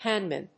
音節háng・man 発音記号・読み方
/‐mən(米国英語)/